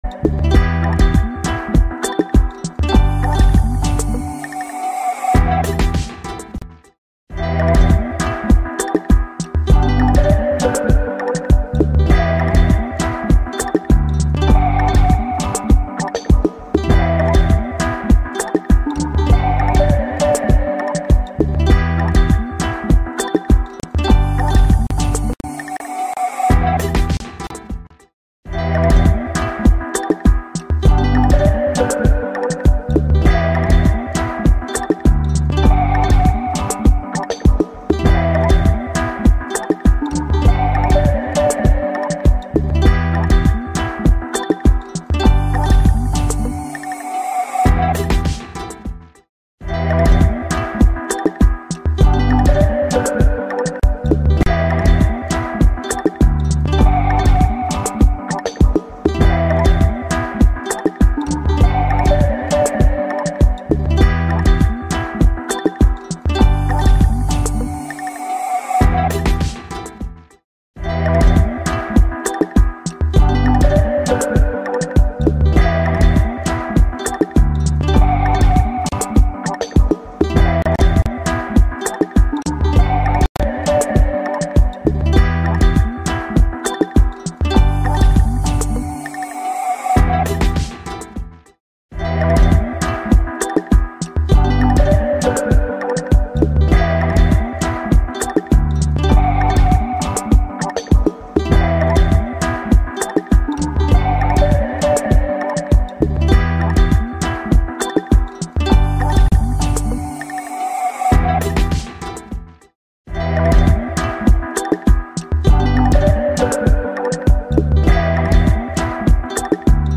Transmisión en directo Rueda de Prensa de la Junta BanRep del mes de junio de 2024